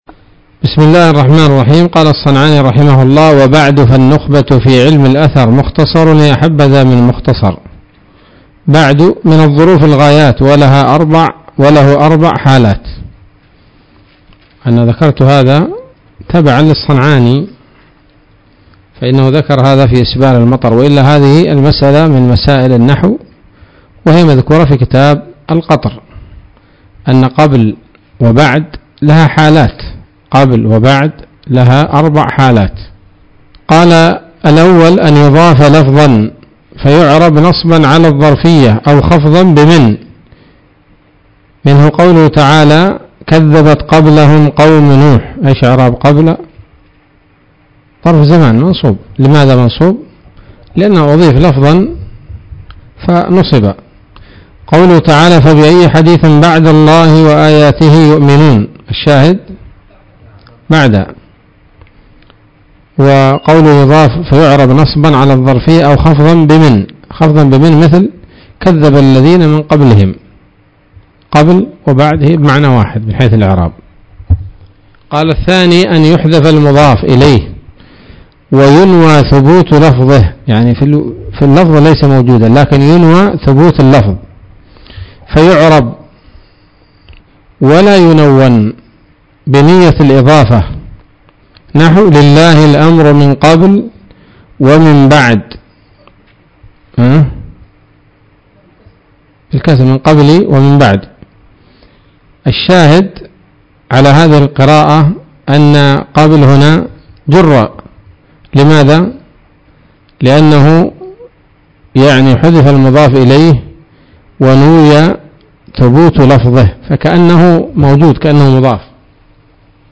⌬ المنظومة مع الشرح ⌬ ليلة الثلاثاء 13 صفر 1445 هـ